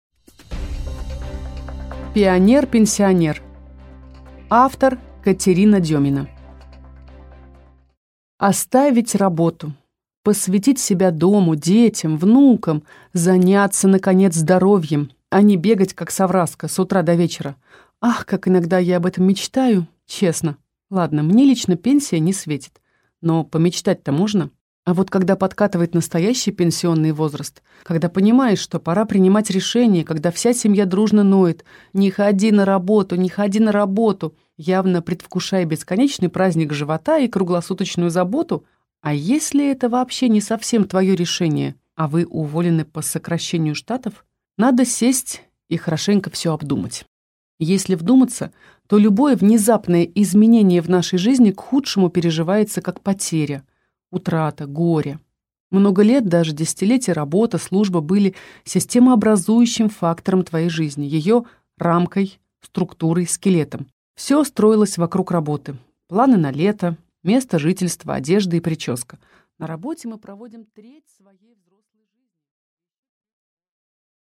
Аудиокнига Пионер-пенсионер | Библиотека аудиокниг